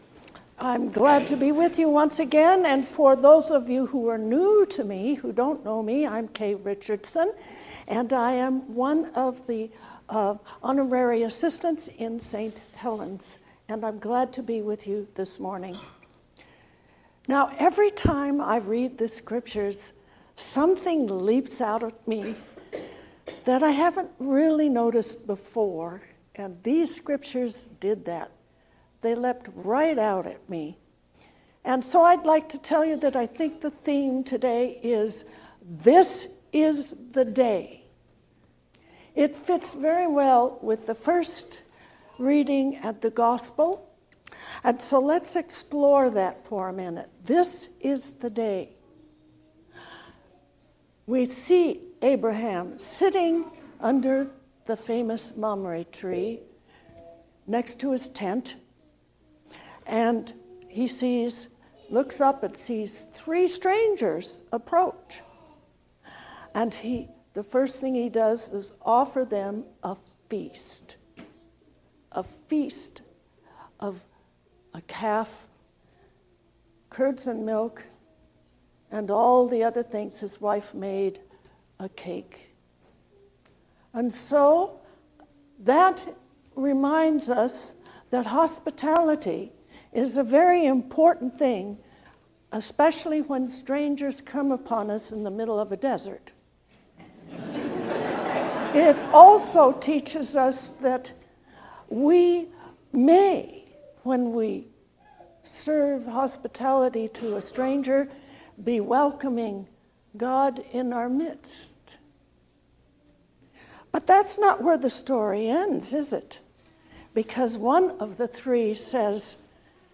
A Sermon for the Third Sunday After Pentecost